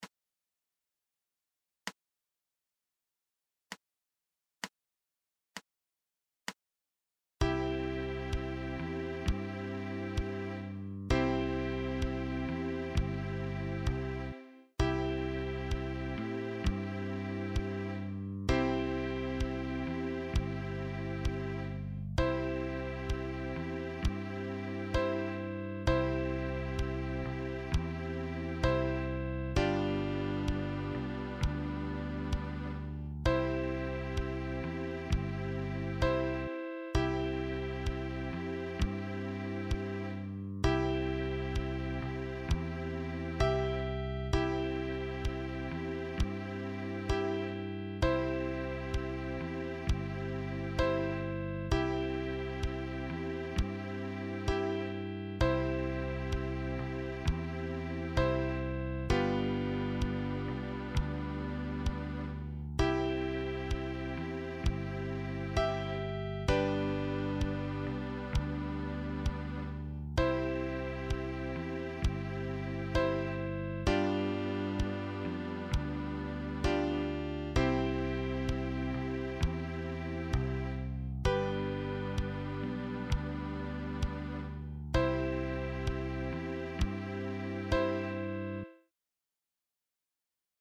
Für Sopran- oder Tenorblockflöte in barocker Griffweise.